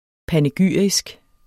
Udtale [ panəˈgyˀɐ̯isg ]